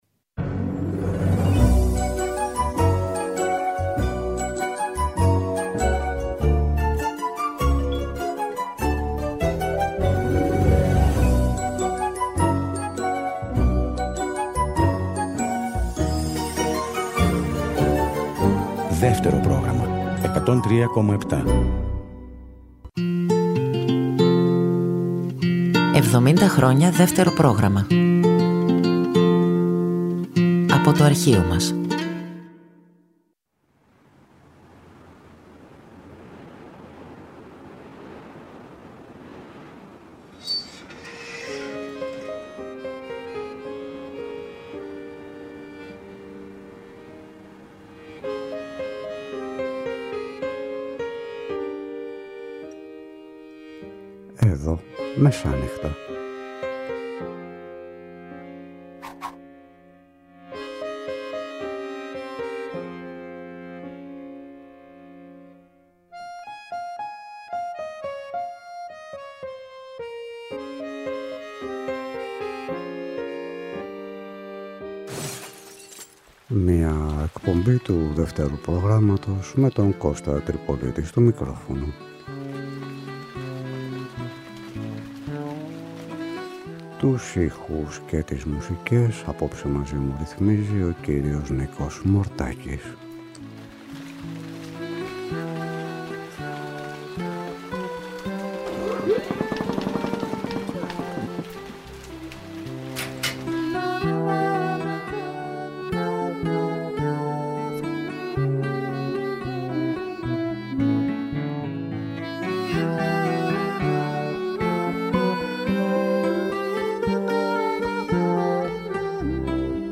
Μία από τις μεταμεσονύχτιες εκπομπές που παρουσίαζε ο Κώστας Τριπολίτης με ποίηση και μουσική.
Κάθε Κυριακή απόγευμα στις 18:00 ακούμε εκπομπές λόγου και μουσικής που είχαν μεταδοθεί παλιότερα από το Δεύτερο Πρόγραμμα.